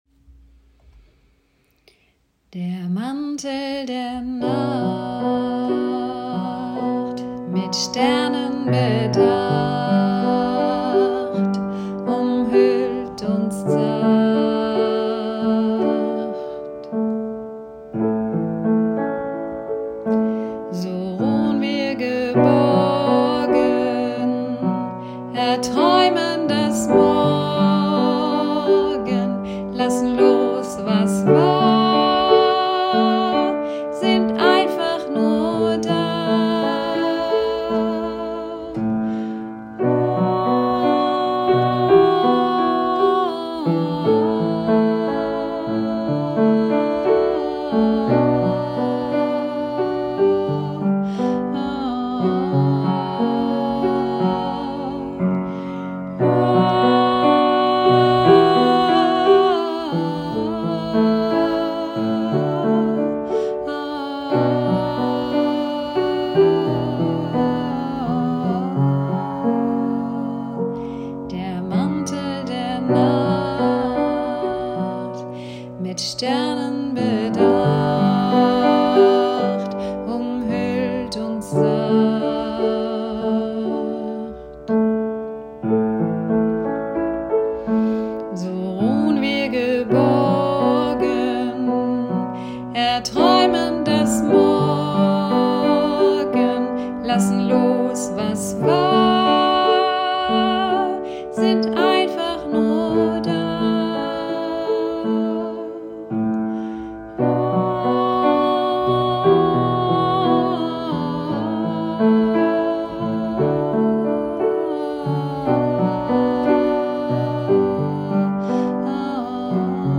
UND: hier noch ein paar einstimmige Lieder